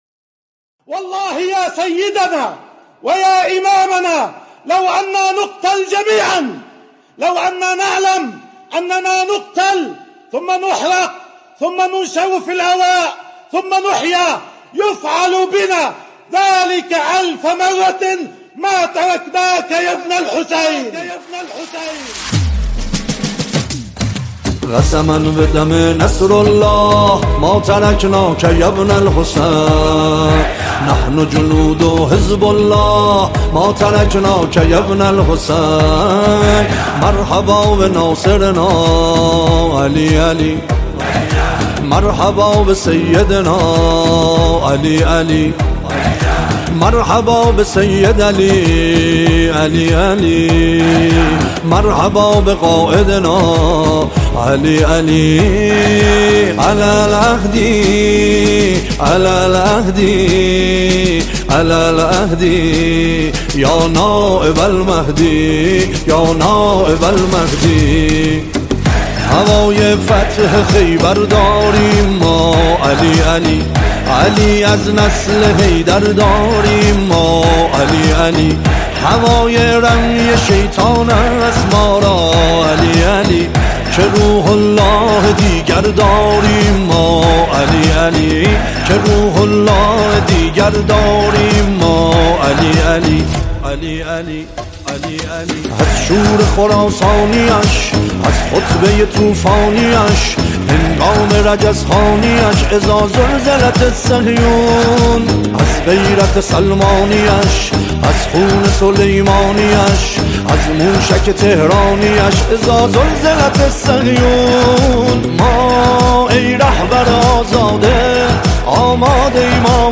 نماهنگ حماسی